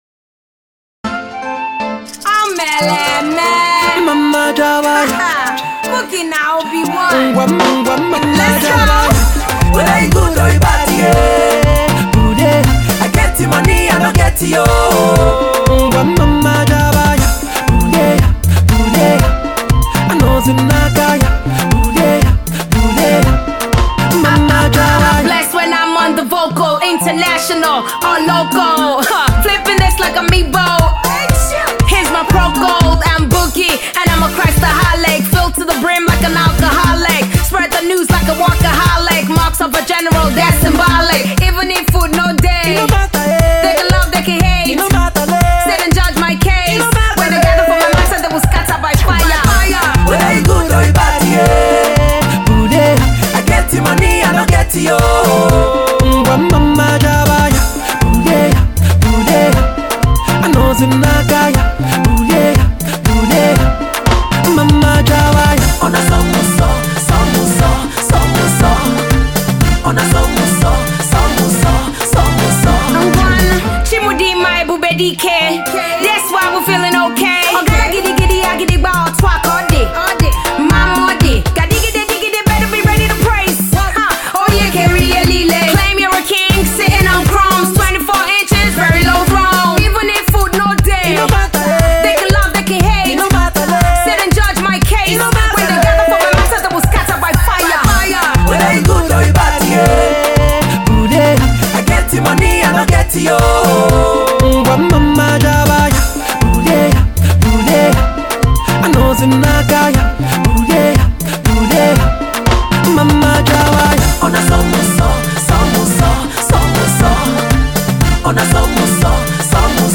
croons energetically between Igbo and English